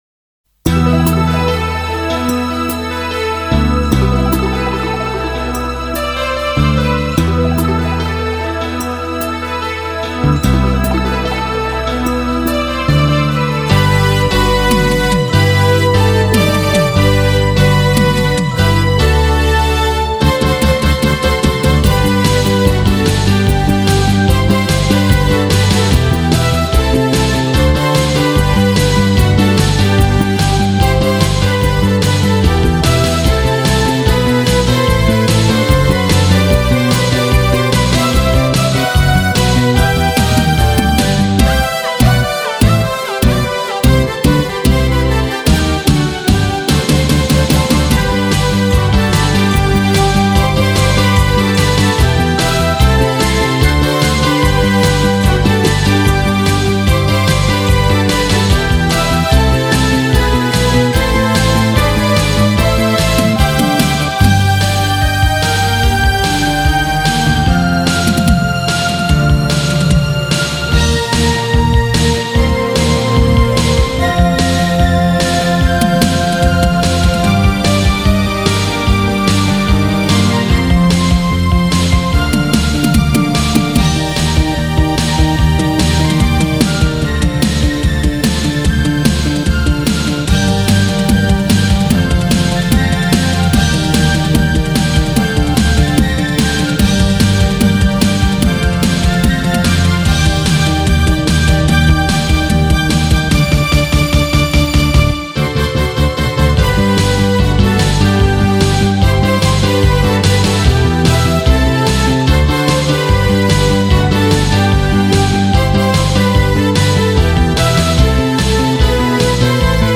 sorozat zenéje